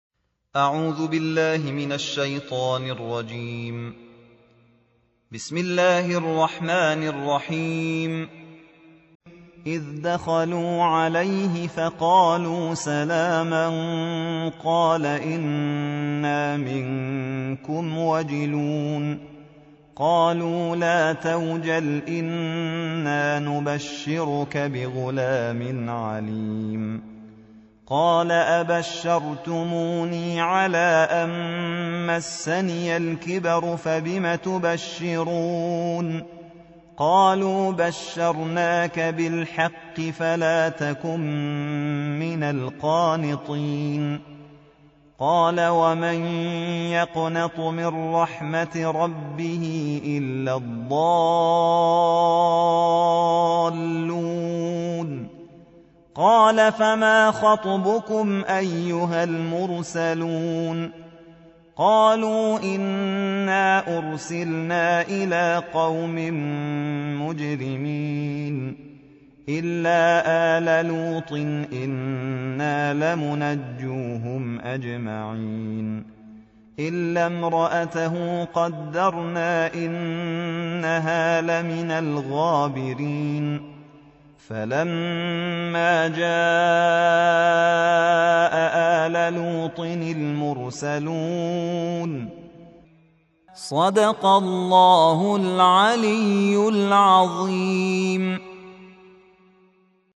قرائت شبانه